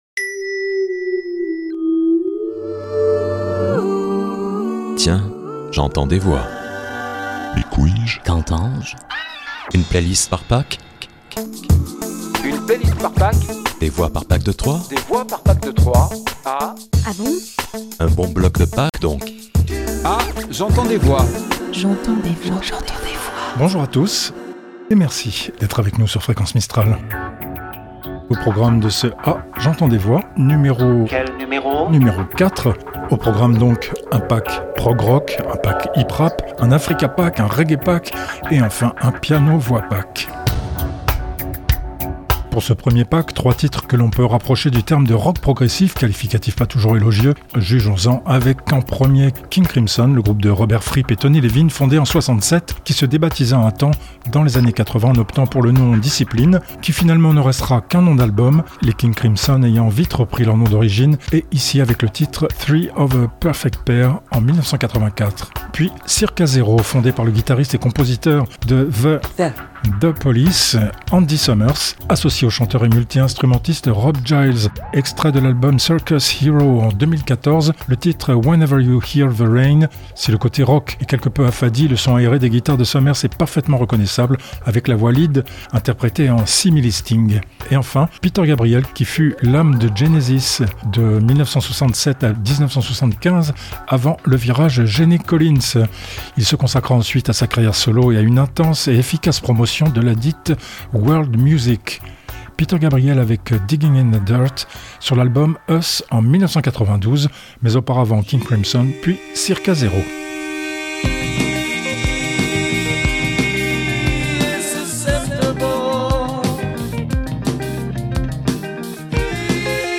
Générique, jingles, voix additionnelles